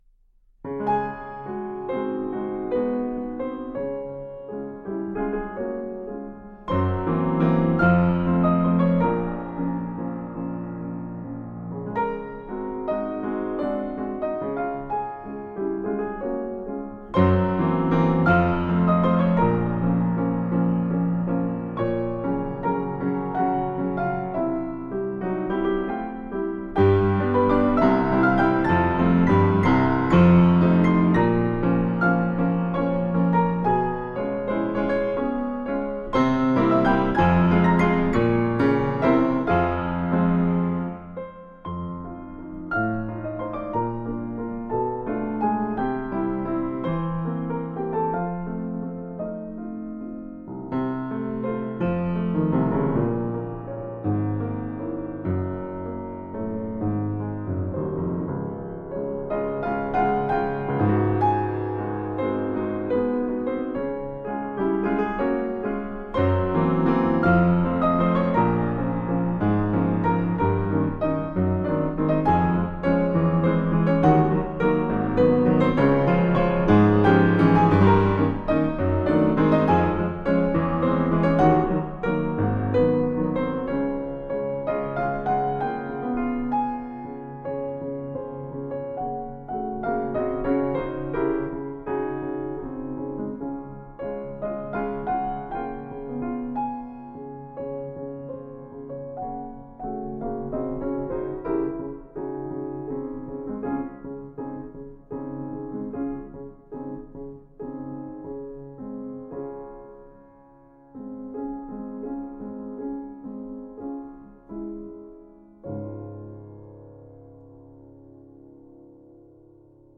Virtuoso piano.